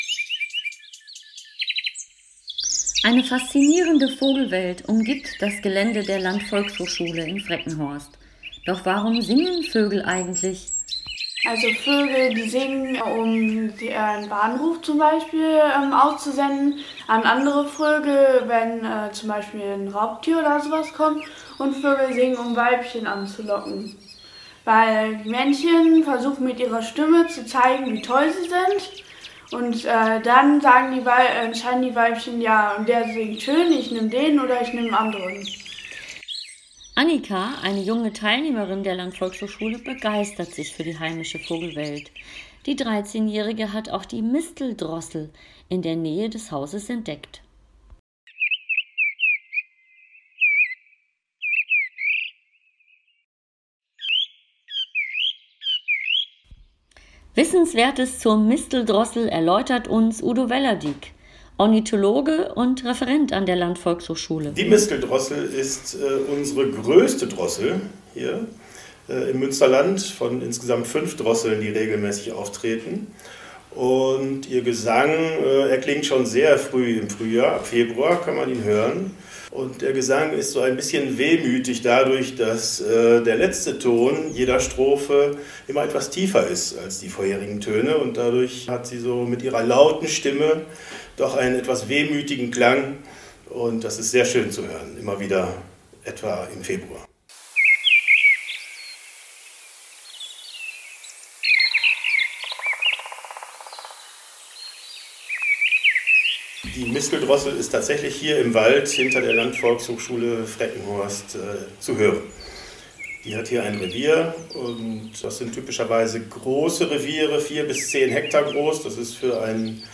Sprecher
Misteldrossel.mp3